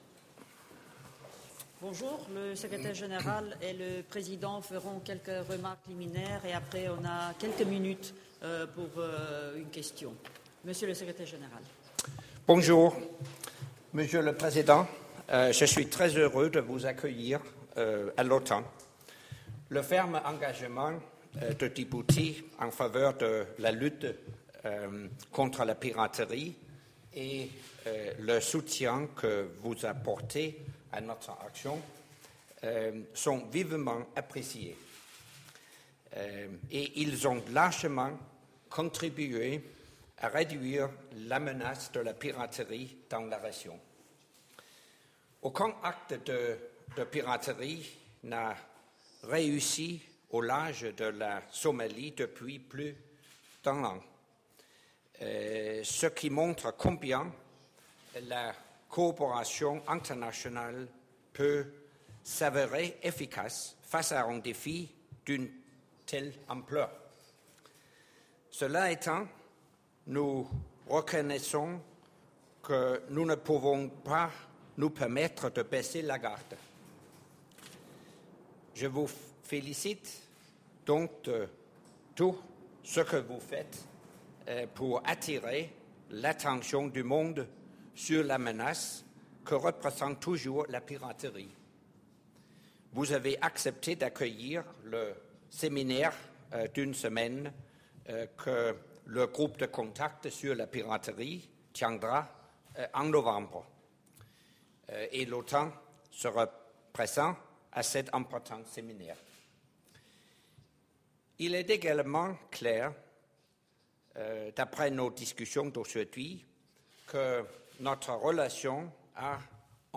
ORIGINAL - Joint press point with the President of Djibouti, Mr. Ismail Omar Guelleh and NATO Secretary General Anders Fogh Rasmussen 16 Sep. 2013 | download mp3 ENGLISH - Joint press point with the President of Djibouti, Mr. Ismail Omar Guelleh and NATO Secretary General Anders Fogh Rasmussen 16 Sep. 2013 | download mp3